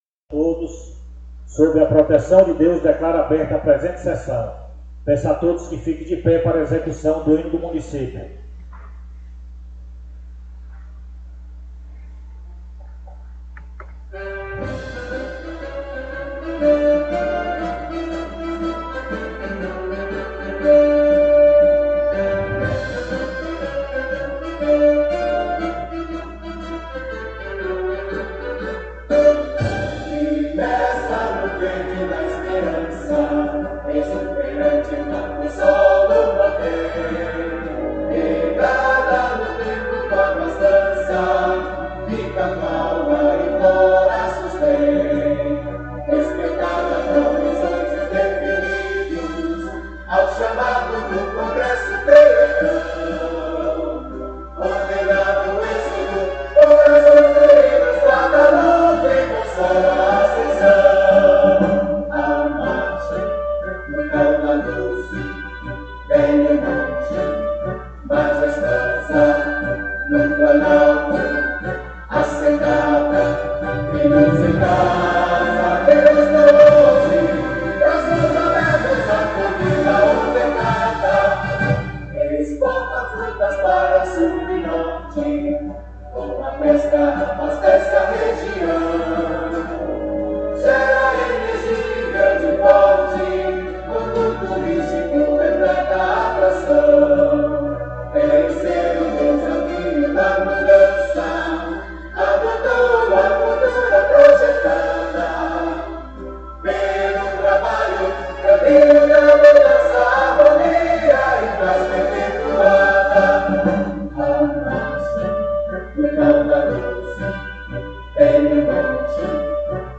Mídias Sociais 6ª SESSÃO ORDINÁRIA PLENÁRIA 13 de abril de 2026 áudio de sessões anteriores Rádio Câmara A Sessão da Câmara de Vereadores ocorre na segunda-feira, a partir das 19:30h.